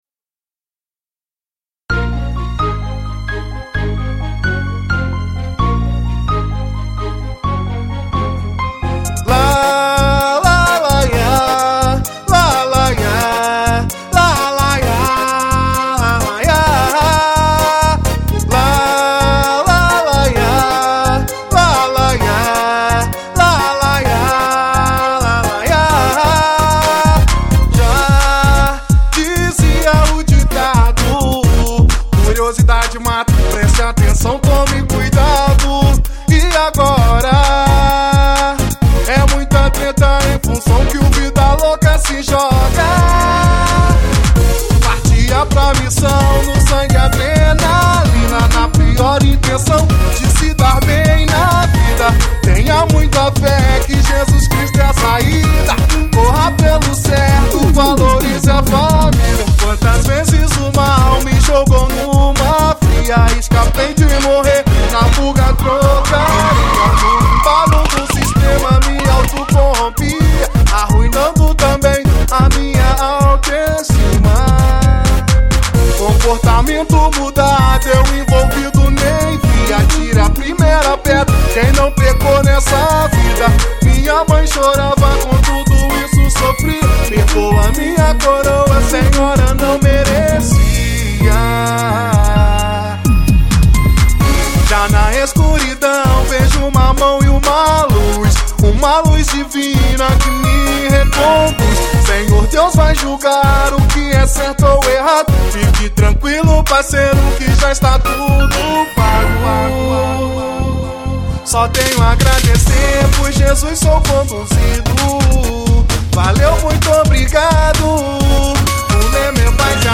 Funk Consciente